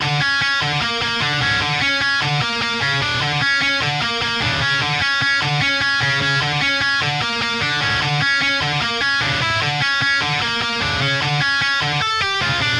金属核心 Scarlxrd型吉他
标签： 150 bpm Trap Loops Guitar Electric Loops 2.15 MB wav Key : Dm FL Studio
声道立体声